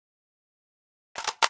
abakan_grenload.ogg